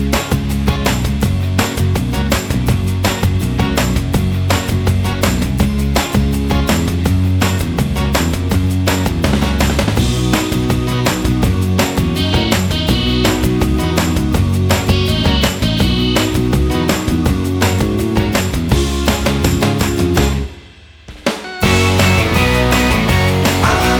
no Backing Vocals Rock 3:10 Buy £1.50